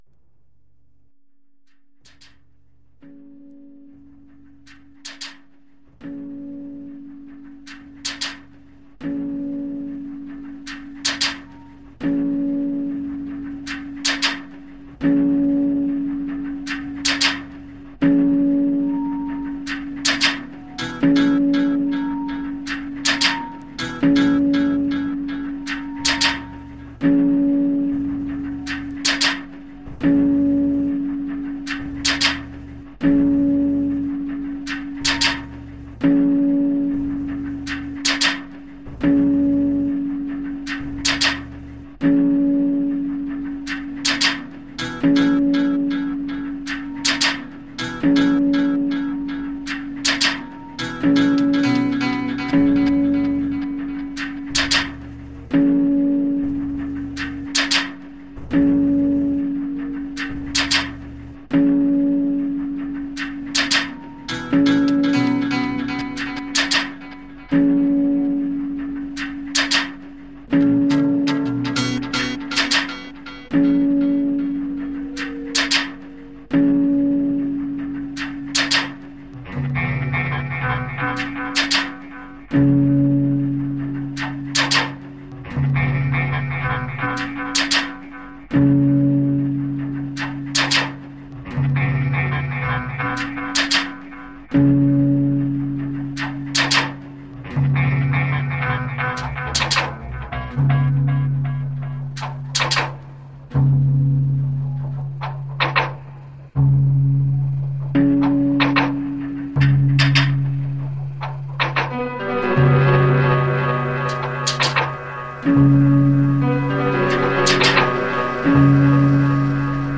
Reimagined version